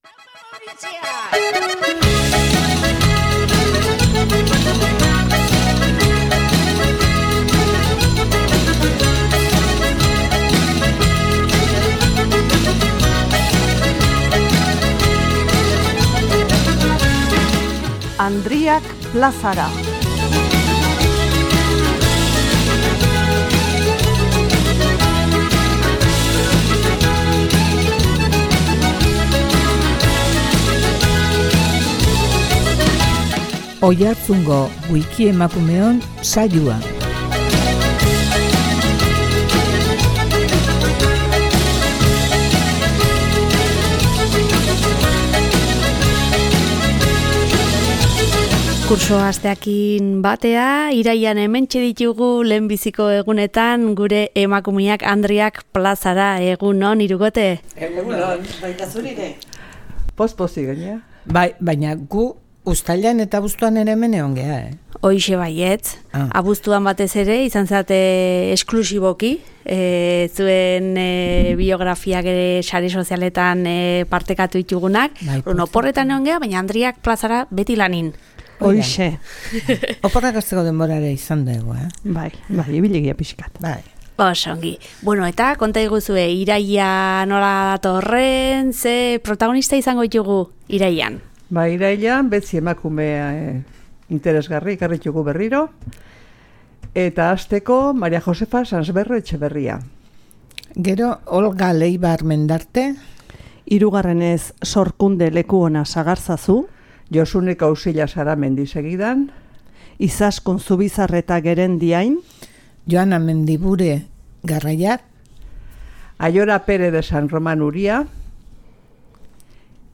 Irailan jaiotako protagonistak izan dituzte hizpide Andriak Plazara saioan Oiartzungo Wikiemakumeok taldeko kideek.